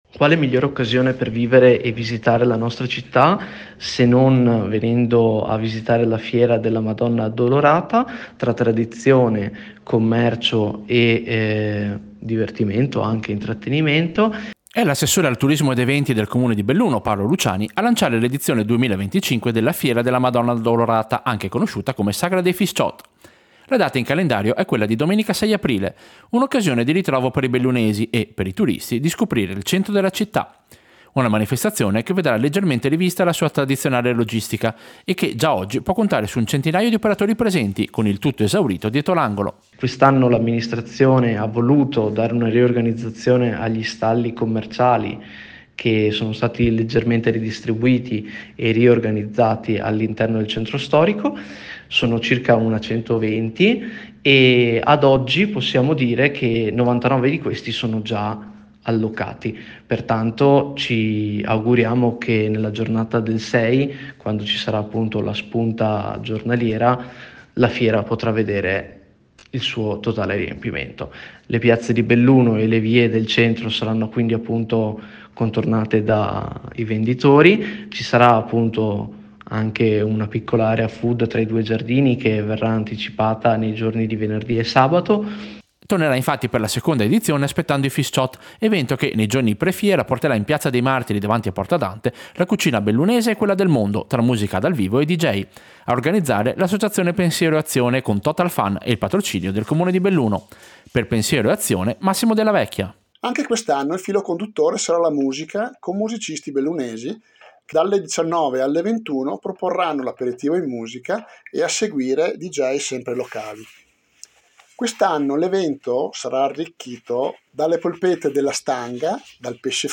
Servizio-Belluno-Addolorata-Fisciot-2025.mp3